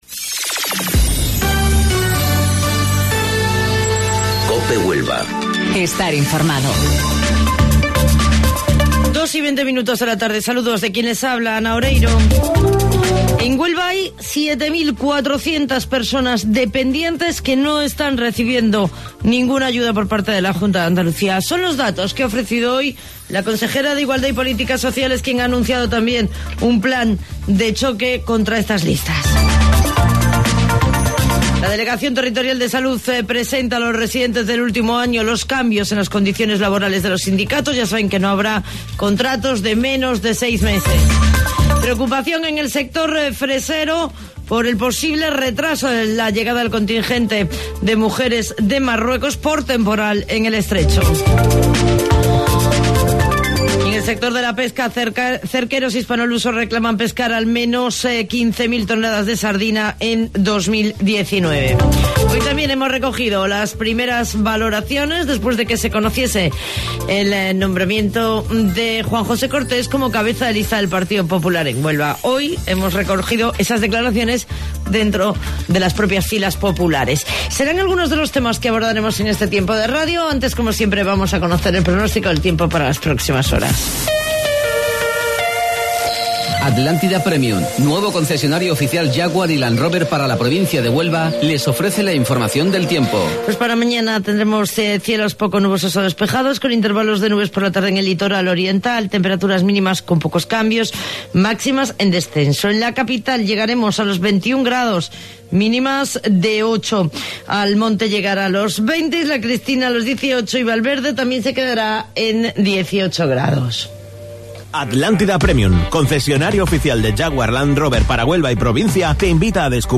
AUDIO: Informativo Local 14:20 del 20 de Marzo